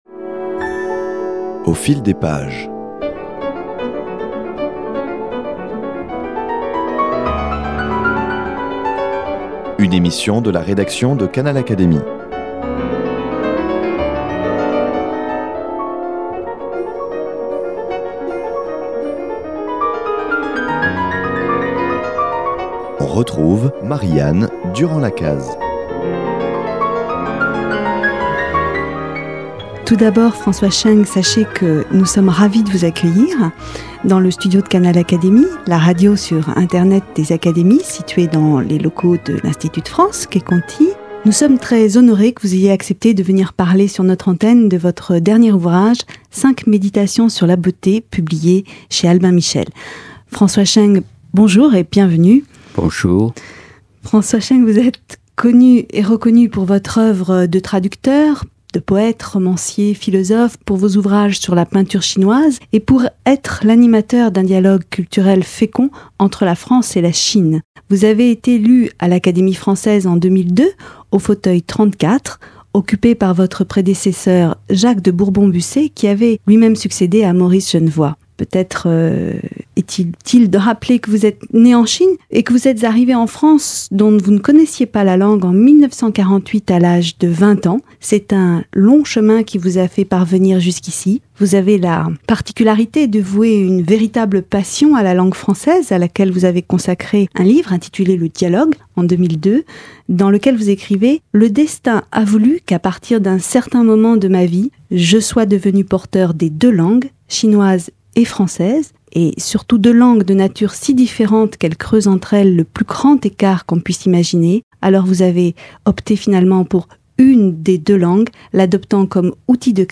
Cinq méditations sur la beauté de François Cheng : rencontre avec « un maître en humanité »